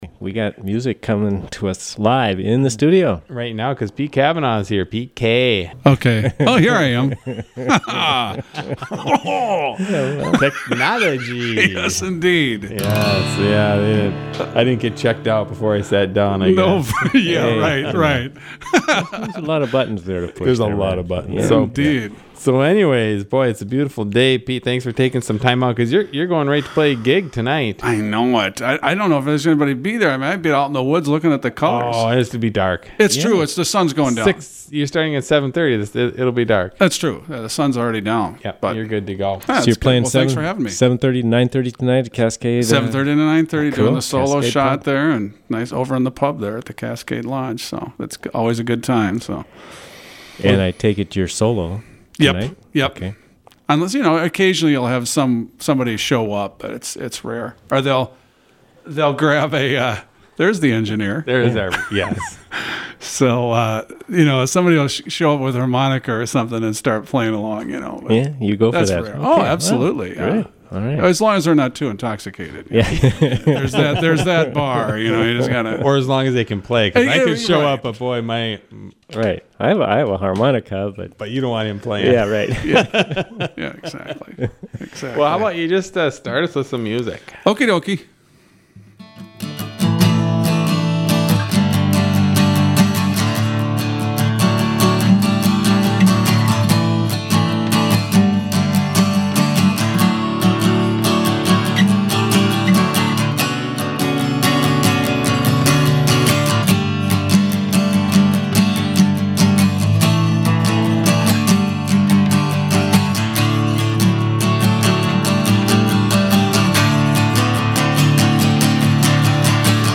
Studio A Sept. 26 for fun chat and great guitar